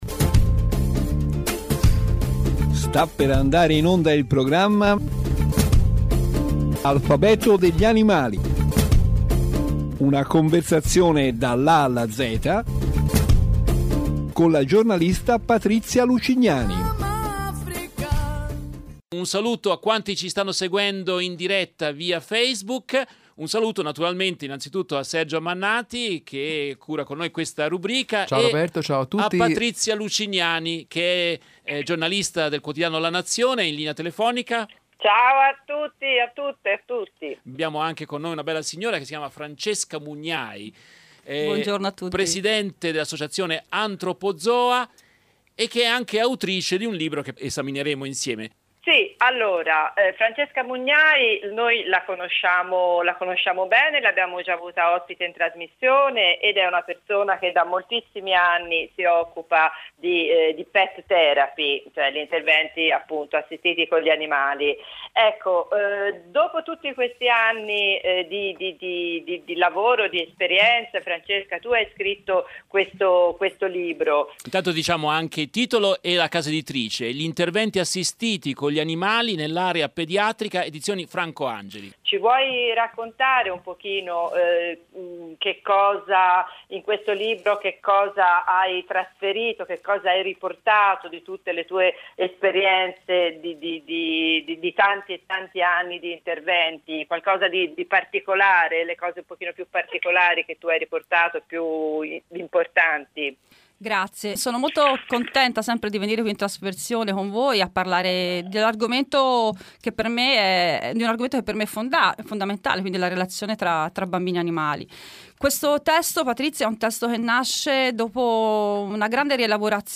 ospitano in studio